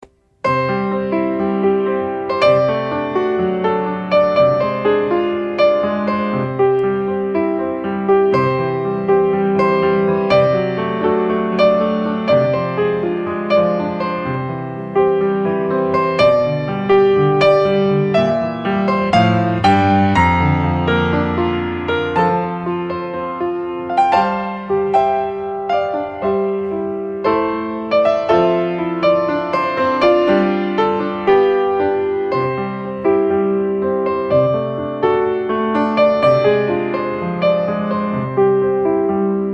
Tipo: music